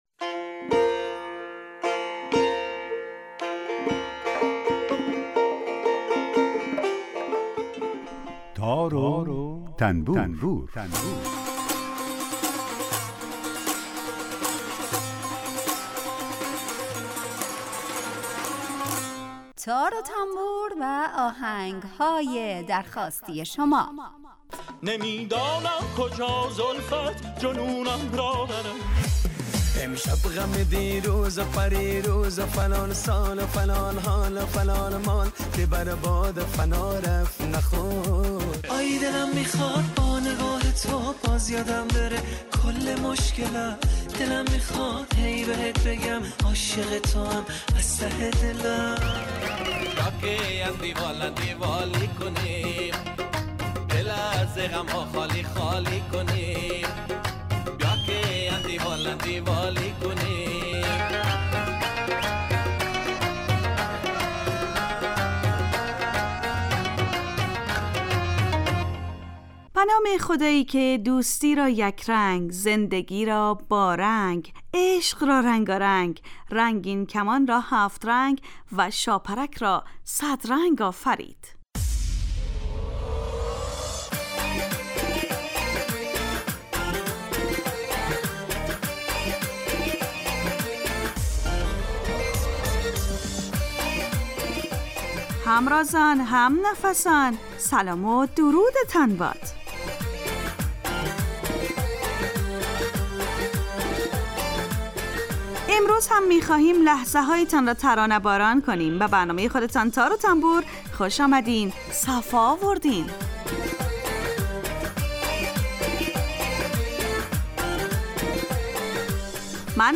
برنامه تار و تنبور هر روز از رادیو دری به مدت 30 دقیقه برنامه ای با آهنگ های درخواستی شنونده ها کار از گروه اجتماعی رادیو دری.